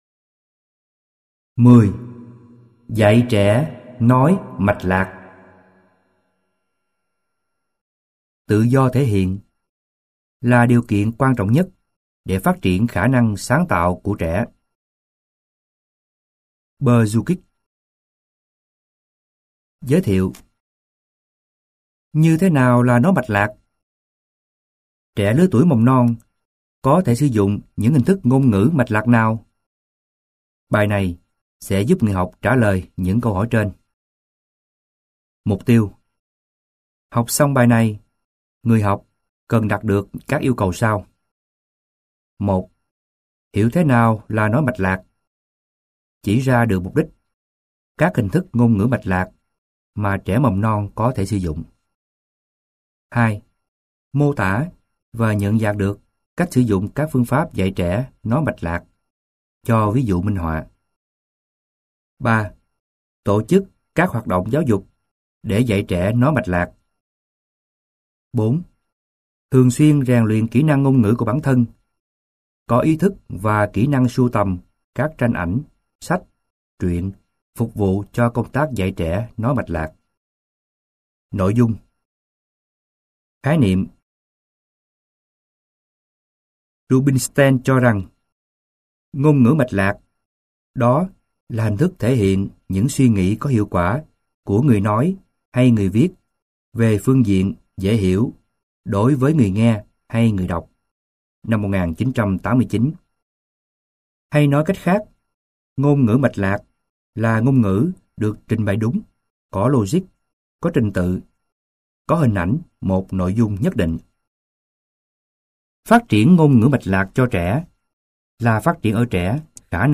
Sách nói Giáo trình Phương pháp phát triển ngôn ngữ cho trẻ mầm non - Sách Nói Online Hay
Giáo trình Phương pháp phát triển ngôn ngữ cho trẻ mầm non Tác giả: TS Nguyễn Thị Phương Nga Nhà xuất bản Bộ GD&ĐT Giọng đọc: nhiều người đọc